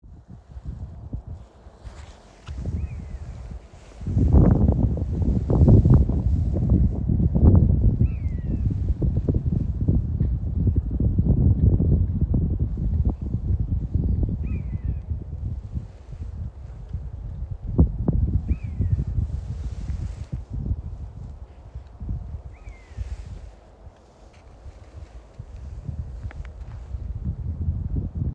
Birds -> Birds of prey ->
Common Buzzard, Buteo buteo
StatusVoice, calls heard